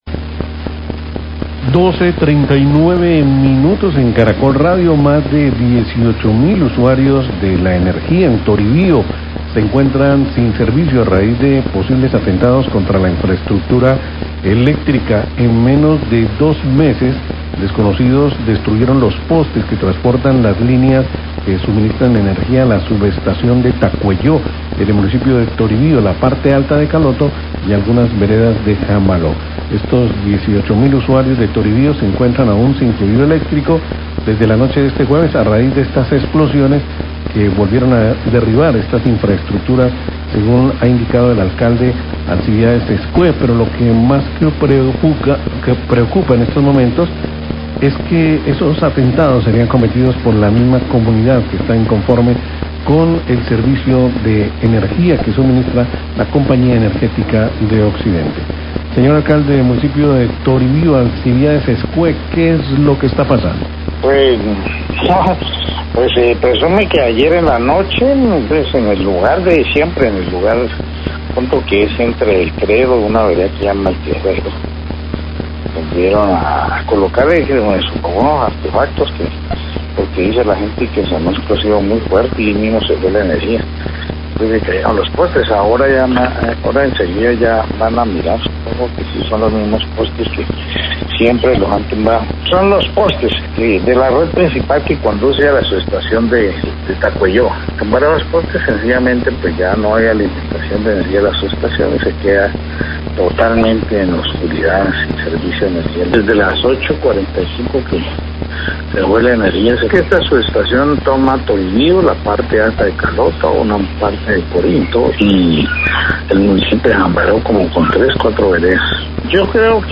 Radio
Más de 18 mil usuarios del servicio de energía en Toribío se encuentran sin el servicio a raíz de posibles atentados contra la infraestructura eléctrica que suministran la energía a la subestación de Tacueyo. Las comunidades están inconformes con el servicio que presta la Compañía Energética. Declaraciones del Alcalde de Toribío, Alcibiades Uscue.